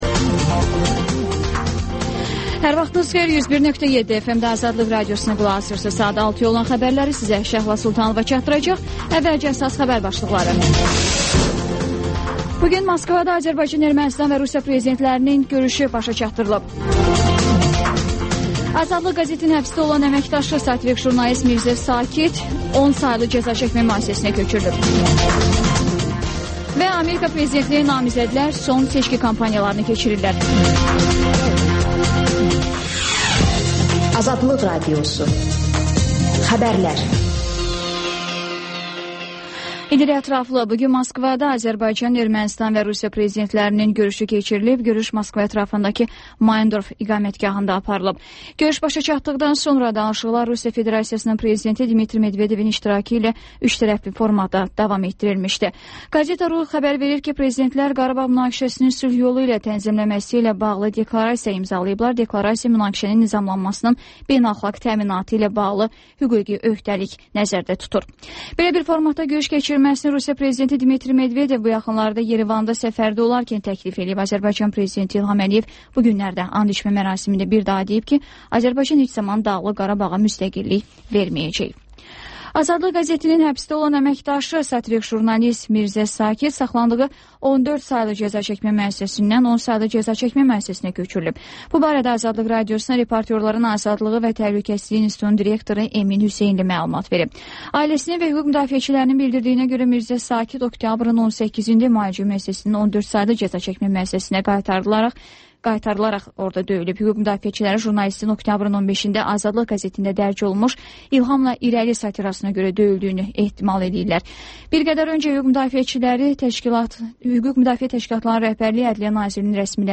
Xəbərlər, QAYNAR XƏTT: Dinləyici şikayətləri əsasında hazırlanmış veriliş, sonda 14-24